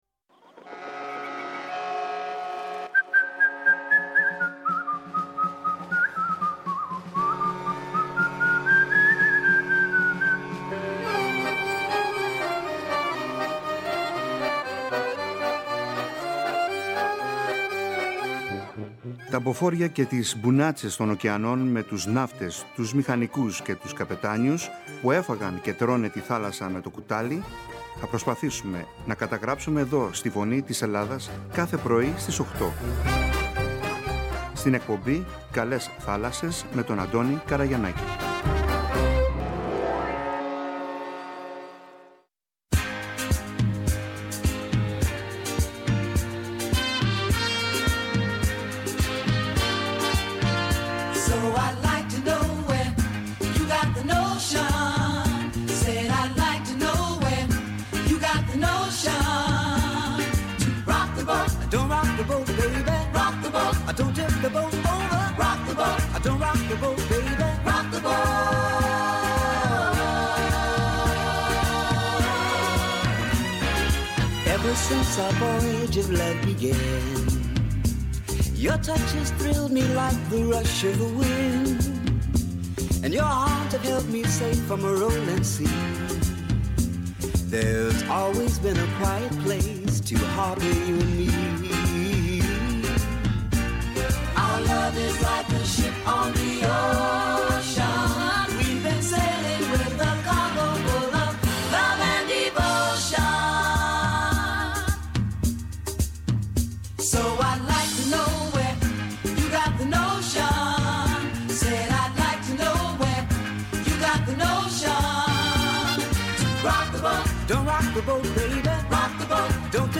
Μας μιλάει για την αρχή της 35χρονης πορείας του στα καράβια με τα καλά και τα κακά της, τις χαρές και της λύπες της. Μας διηγείται με τον δικό του χαρισματικό τρόπο πότε πρωτομπαρκάρισε.